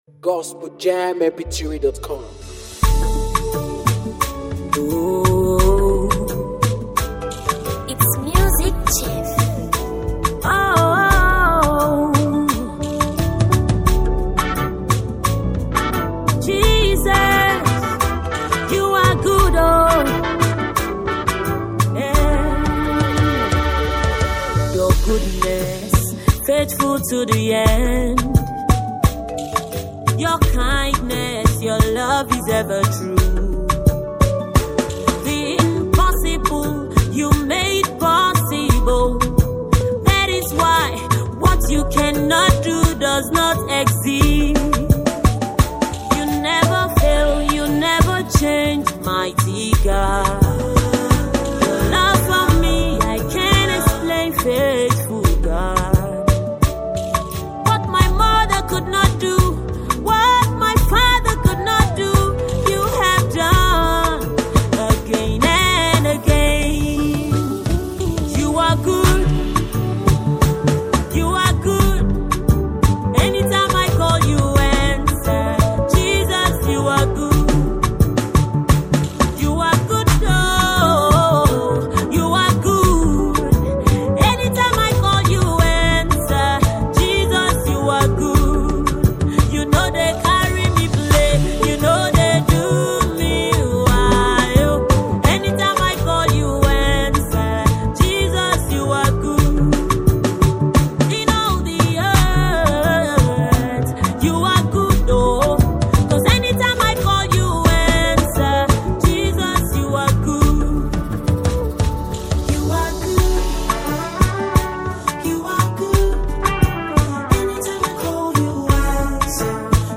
powerful worship song
With its soulful melody and heartfelt delivery